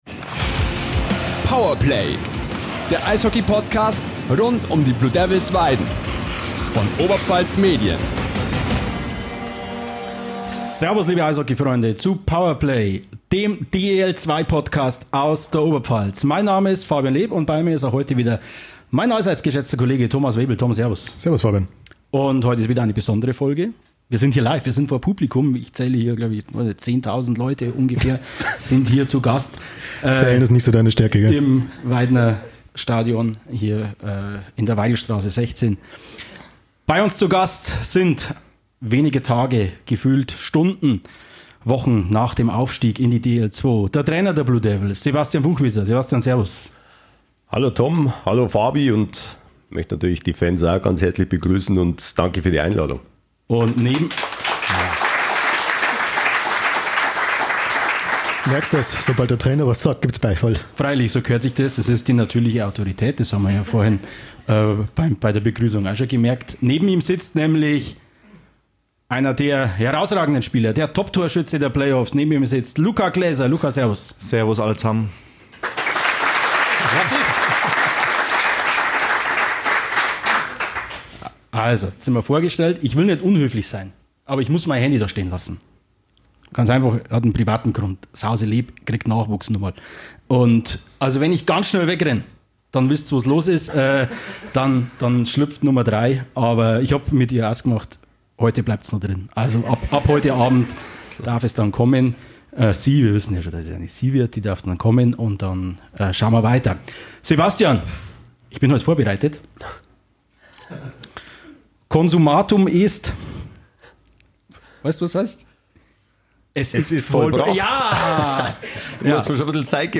Die Fanfragen übernahmen dieses Mal die Zuschauer vor Ort.